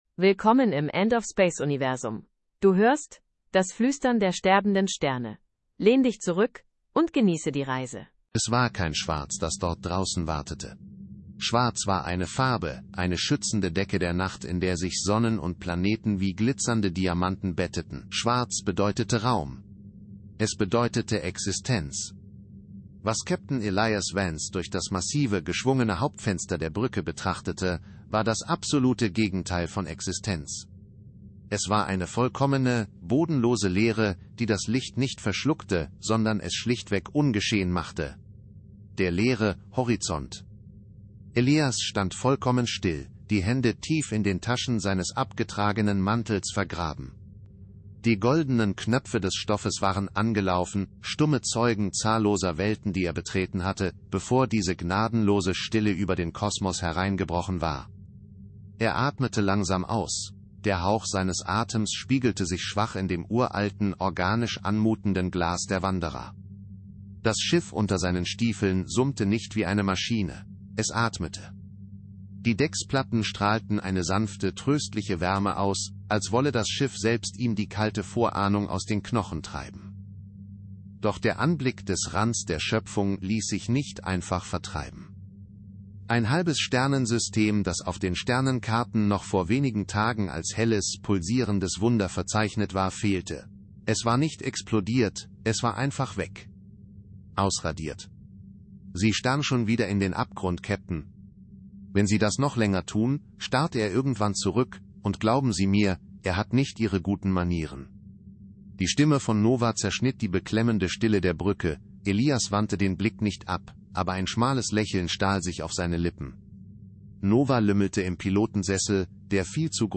End of Space ist ein endloses Sci-Fi Hörbuch-Epos.